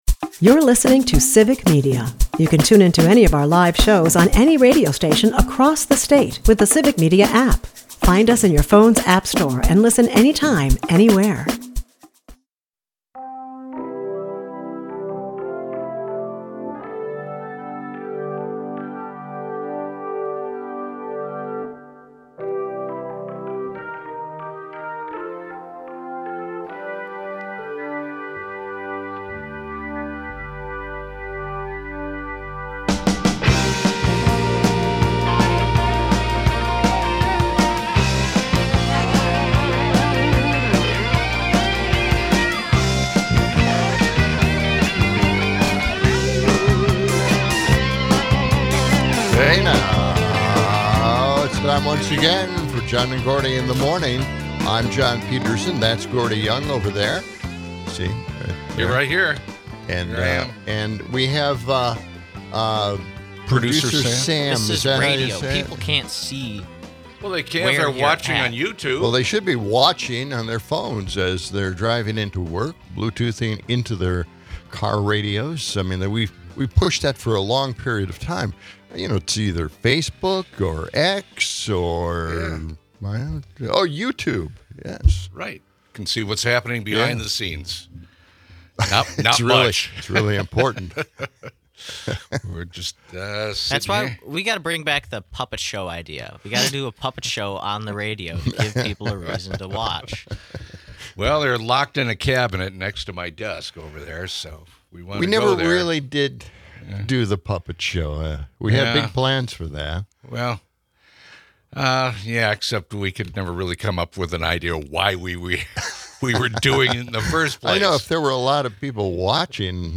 The hosts lament political absurdity, from Trump's egg price faux pas to Dr. Oz's health hacks. Amidst the comedic banter, a chilling whistleblower revelation warns of Russia tapping into the National Labor Relations Board.